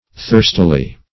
Thirstily \Thirst"i*ly\, adv.